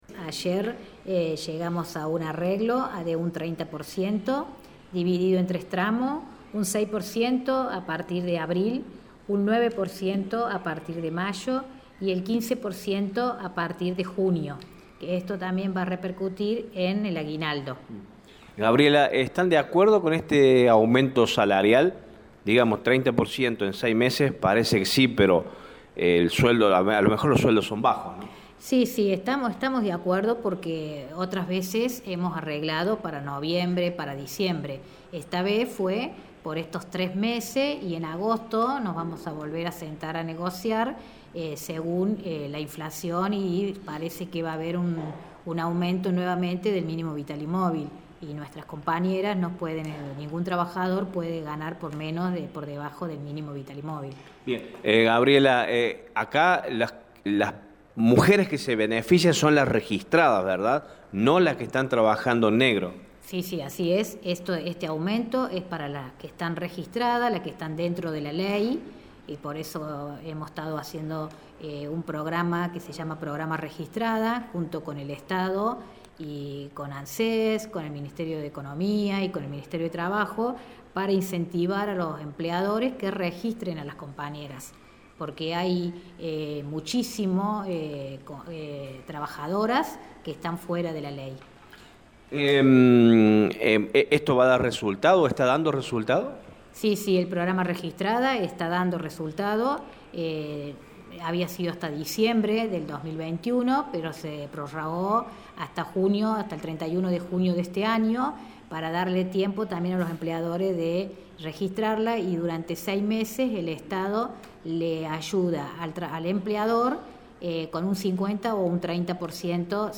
habló con Radio Show y brindó detalles del acuerdo salarial.